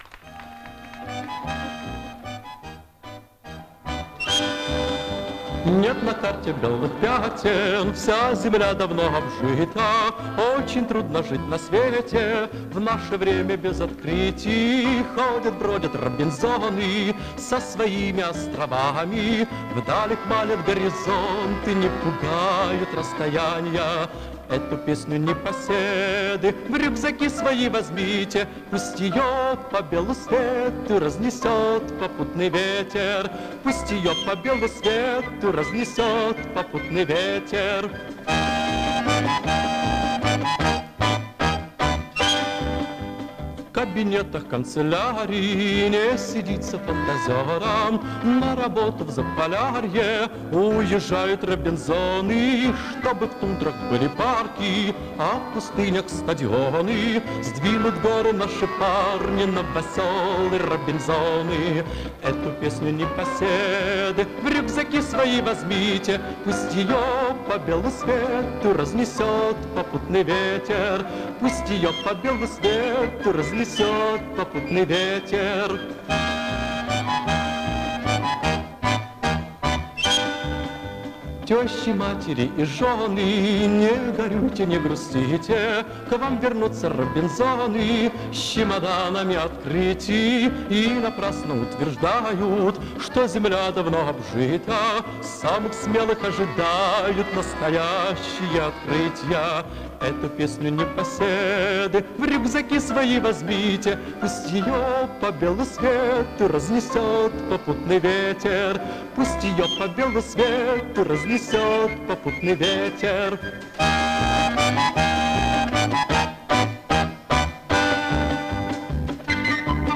версия с полным музыкальным вступлением